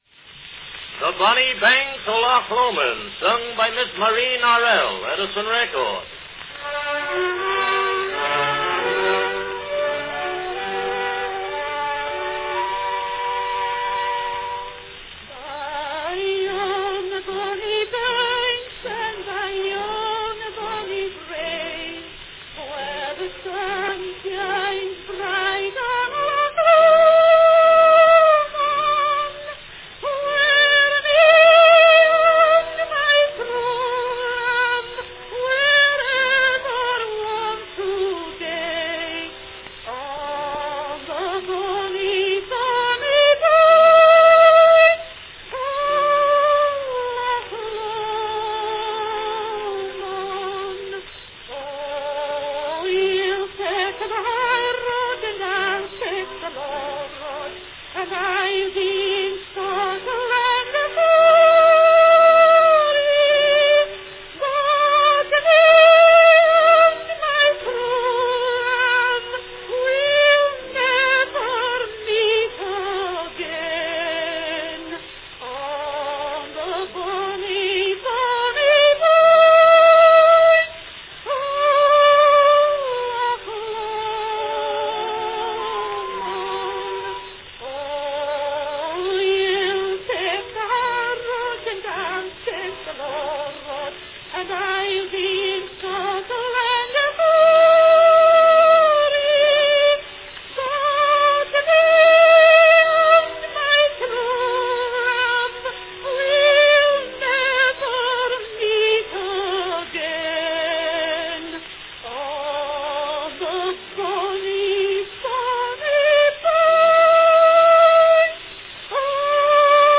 Cylinder of the Month
traditional Scottish folk song
Category Soprano
Sung with orchestra accompaniment.